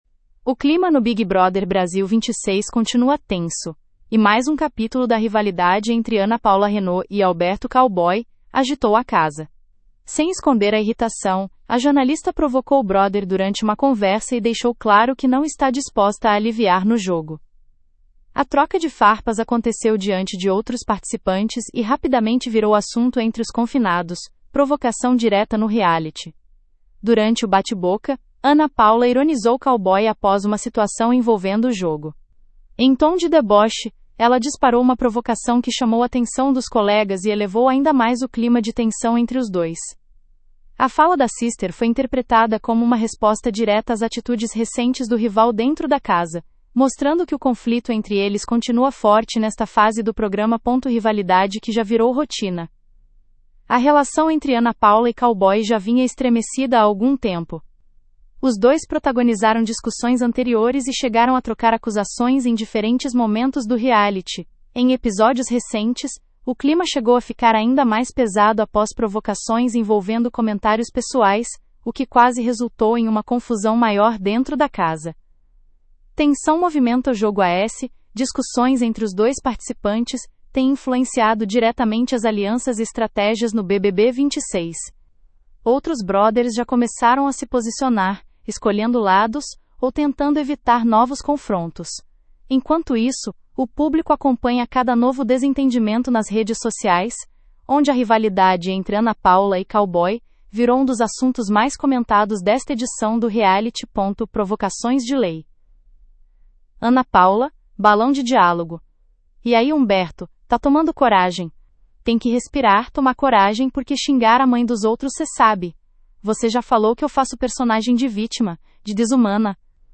Em tom de deboche, ela disparou uma provocação que chamou atenção dos colegas e elevou ainda mais o clima de tensão entre os dois.